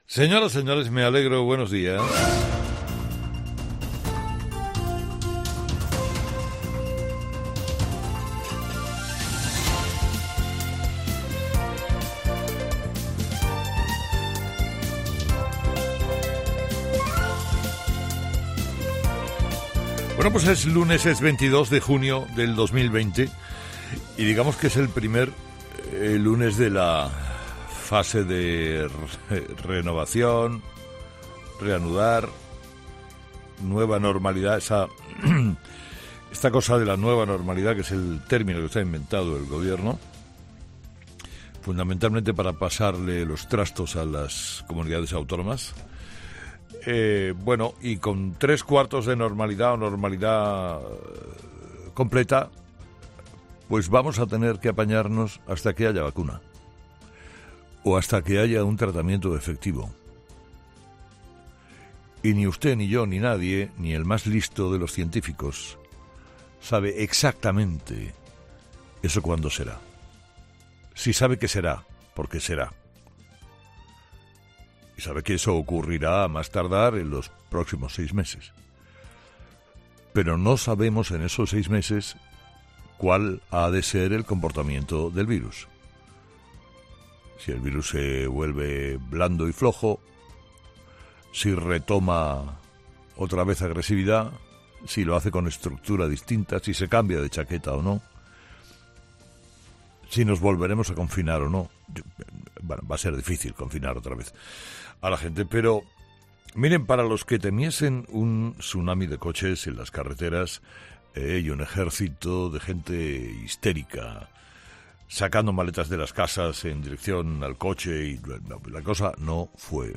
En su primer programa tras el fin del estado de alarma, Carlos Herrera ha comenzado su monólogo de las seis de la mañana informando precisamente sobre la situación sanitaria en España en este inicio de la así llamada "nueva normalidad".